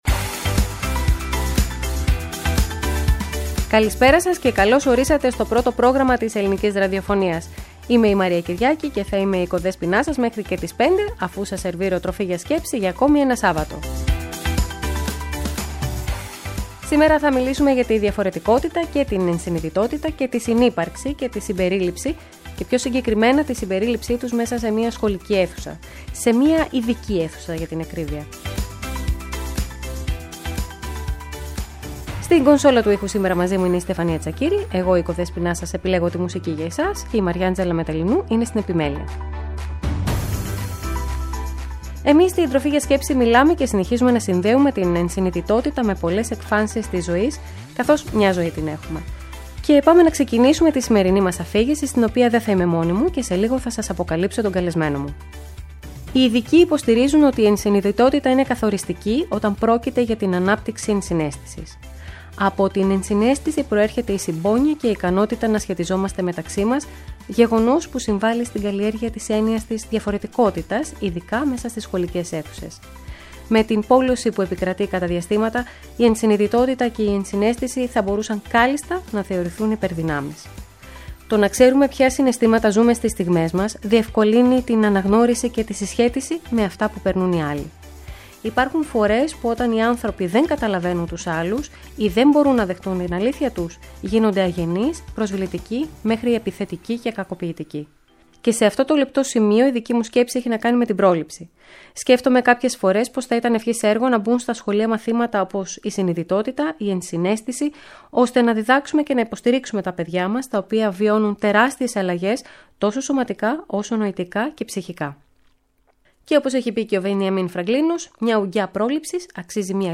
Άνθρωποι που αγαπούν το φαγητό, διάσημοι και βραβευμένοι σεφ συμμετέχουν στην εκπομπή για να μοιραστούν την τεχνογνωσία τους και τις ιδέες τους για μια καλύτερη ζωή.